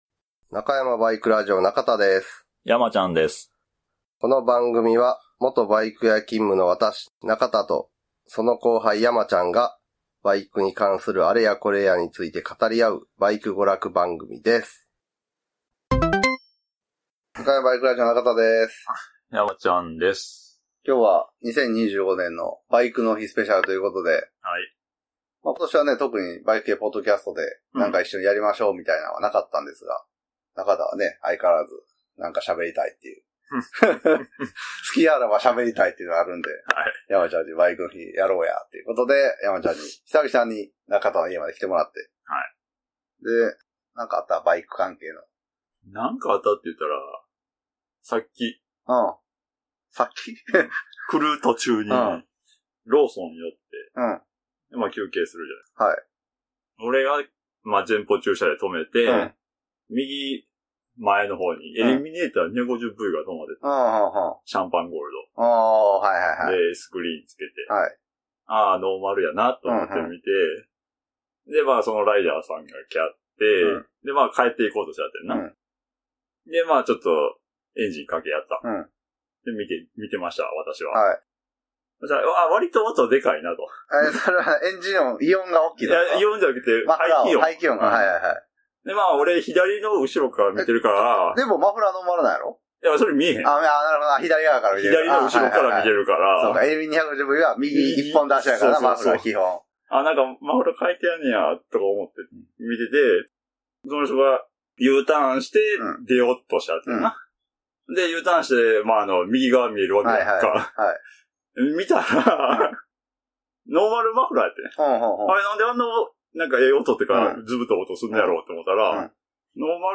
＜＜ご注意＞＞ 独断と偏見に基づいて会話しておりますので、正統派ライダーの方は気分を害する恐れがあります。